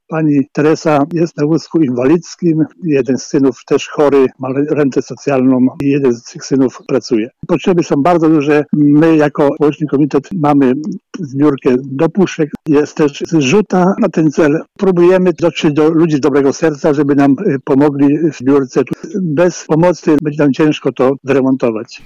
Potrzebują nowego domu, a jego odbudowa wymaga ogromnych nakładów finansowych. Mówi Bogdan Grabski, sołtys Barzkowic